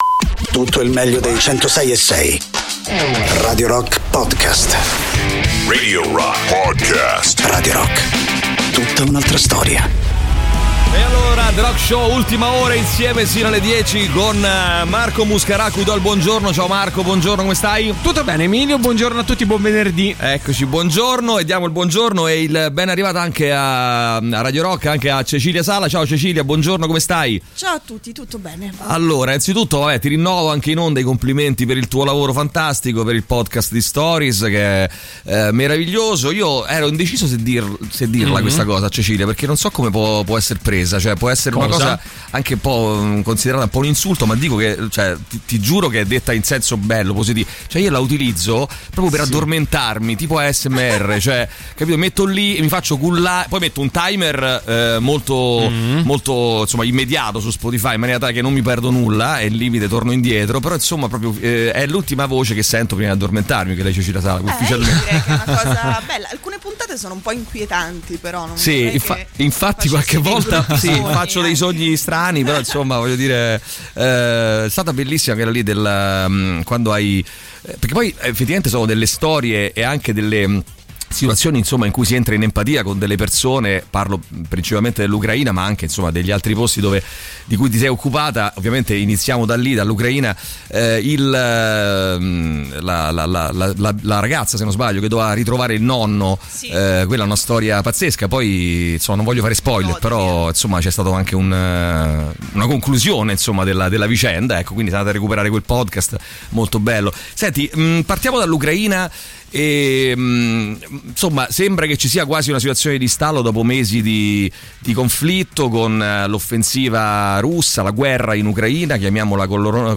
Interviste: Cecilia Sala (21-10-22)